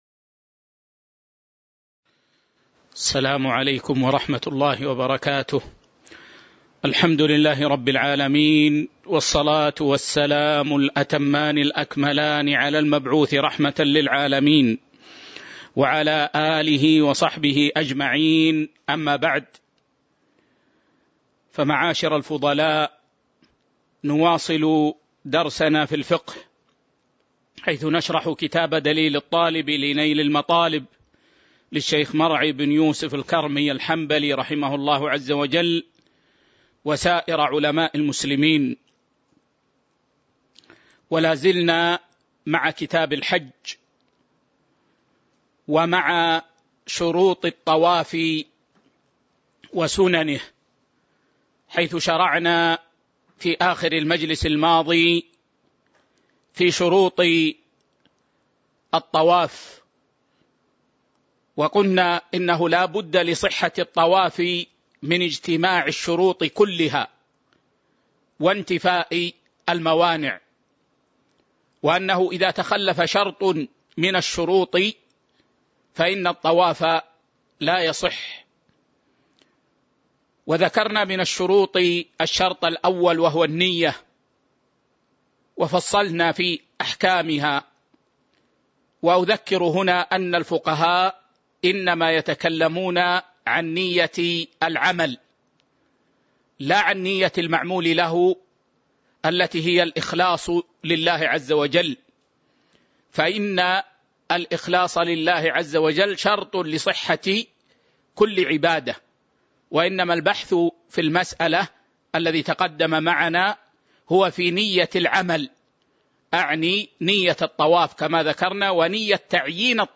تاريخ النشر ١١ ربيع الثاني ١٤٤٢ هـ المكان: المسجد النبوي الشيخ